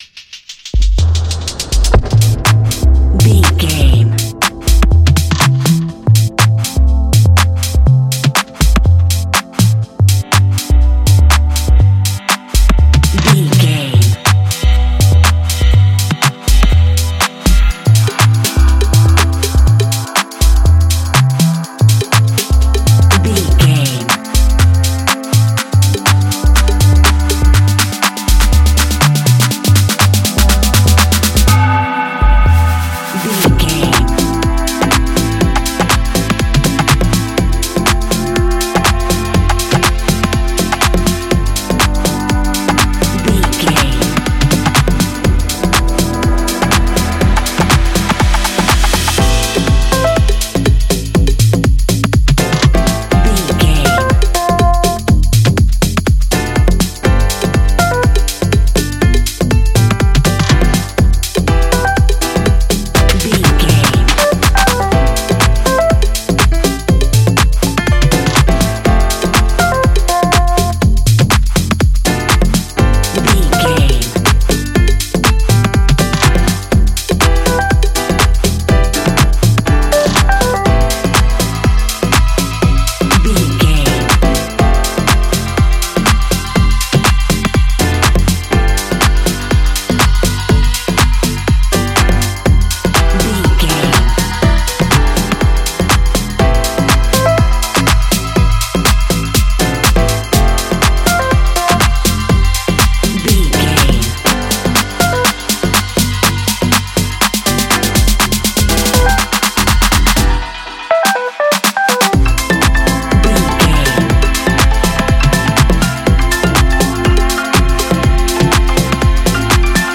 Fast paced
In-crescendo
Uplifting
Ionian/Major
Ableton live
Fast
groovy
Pulsating
energetic